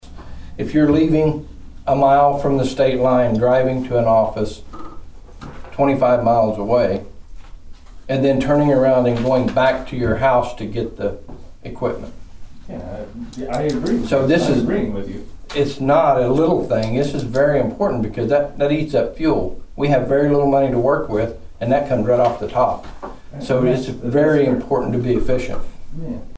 The Nowata County Commissioners met for a regularly scheduled meeting on Monday morning at the Nowata County Annex.
Commissioner Friddle discussed the challenges of a county-wide tax.
Friddle on Fringe.mp3